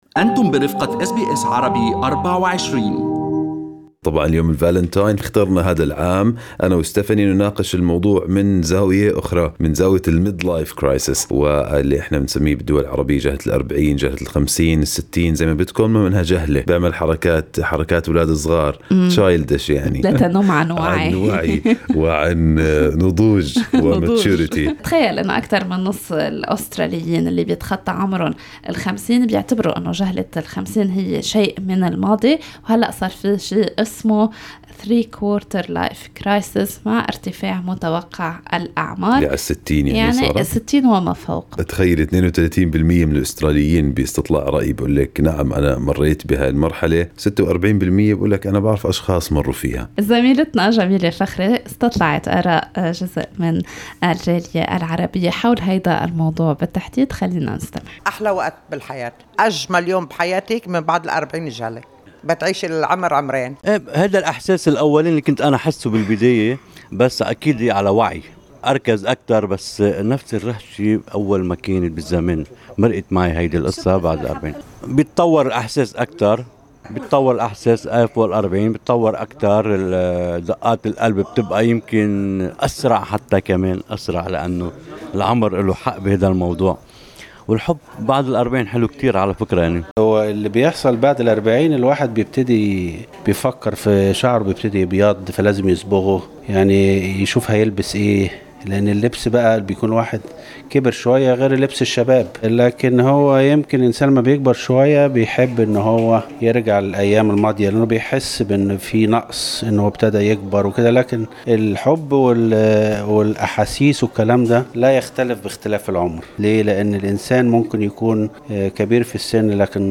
استطلعنا آراء من الشارع حول ما اذا كانت أزمة منتصف العمر تعصف بالجميع أم لا.